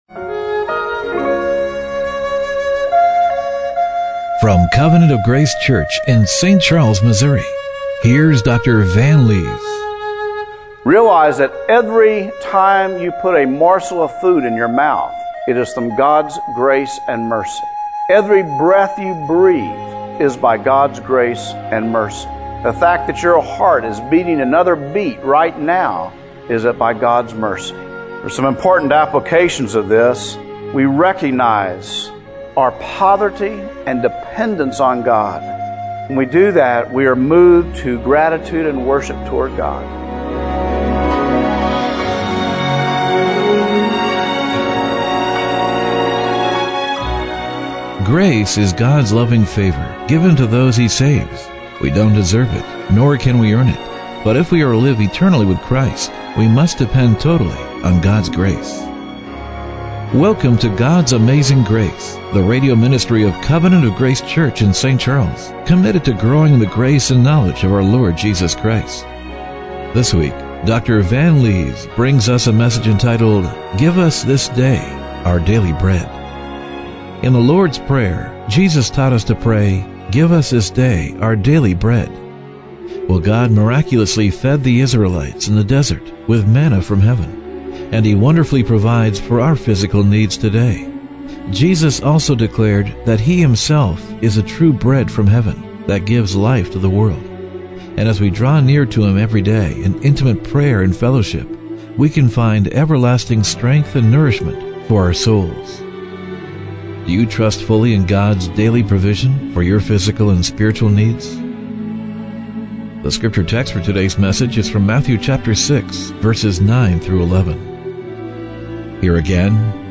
Matthew 6:9-11 Service Type: Radio Broadcast Do you trust fully in God's daily provision for your physical and spiritual needs?